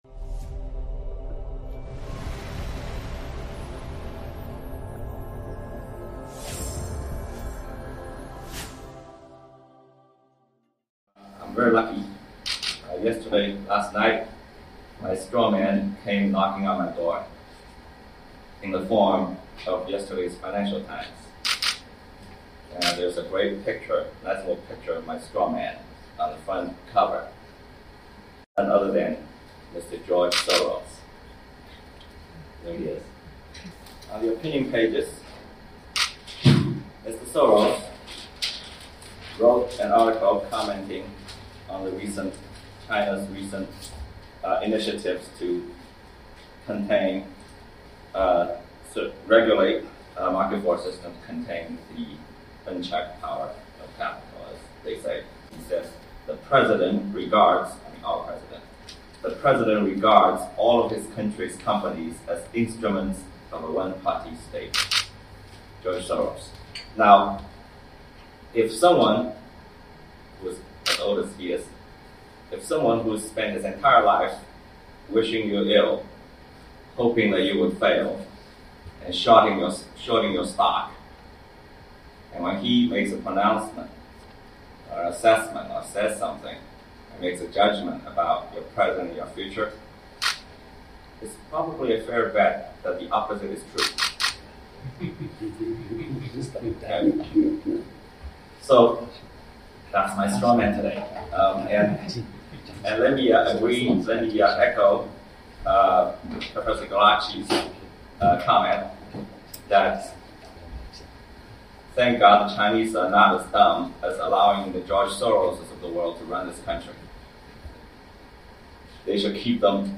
Speaking at the meeting, he explained the relationship between the country and capital.